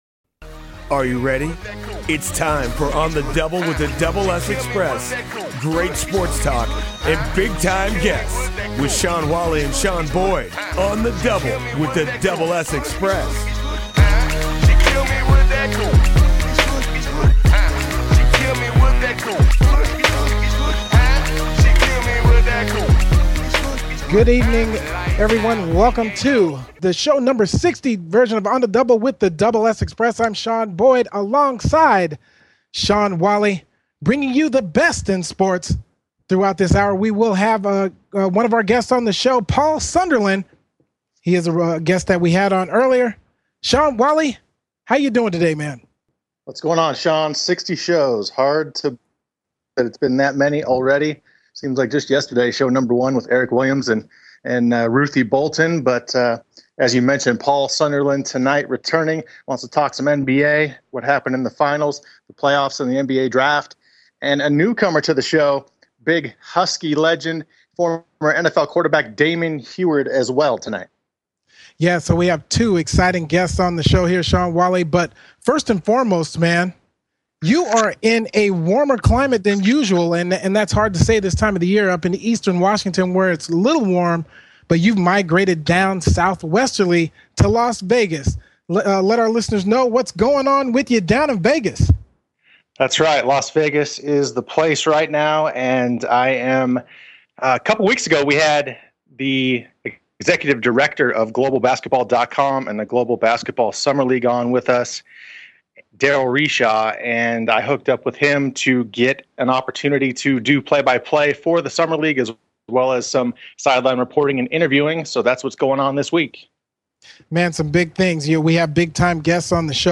Guests: Damon Huard; Paul Sunderland
On the Double show 60 has Big Time Guests Damon Huard & Paul Sunderland join the show!